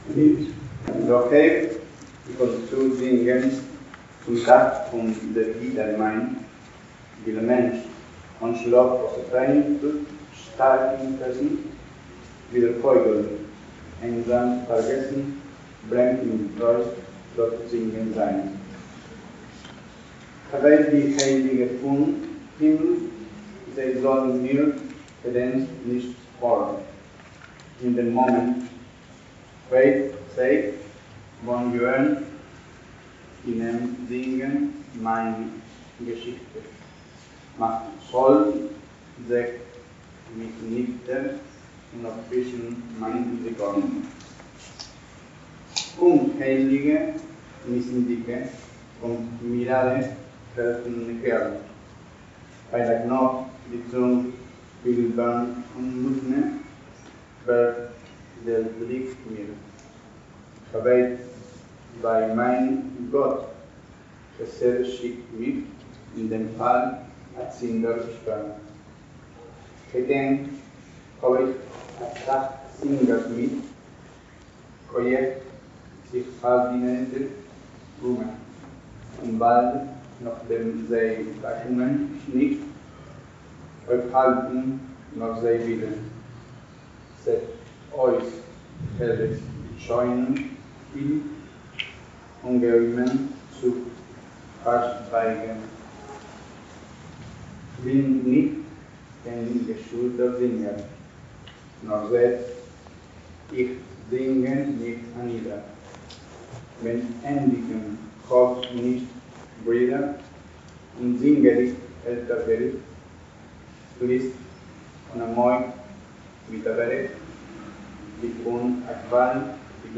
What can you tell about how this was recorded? Evento: Jornadas Políglotas Martín Fierro (City Bell, 1° de diciembre de 2023)